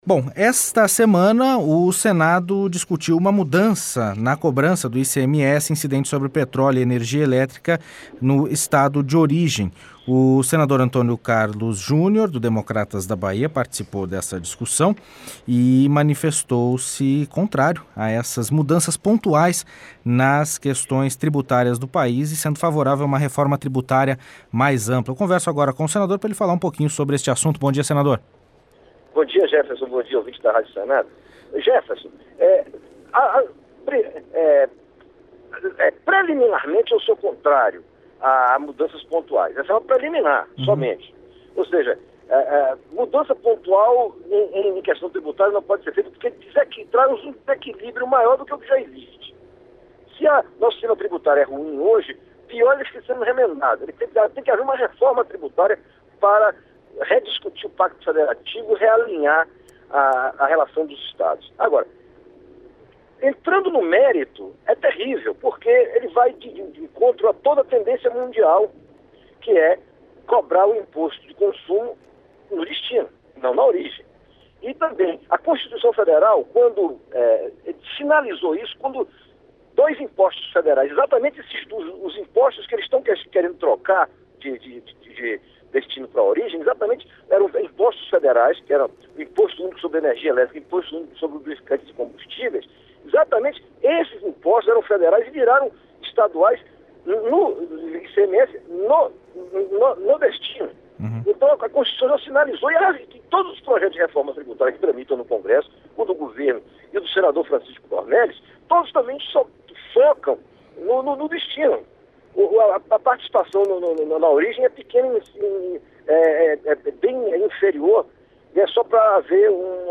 Entrevista com o senador Antonio Carlos Júnior (DEM-BA).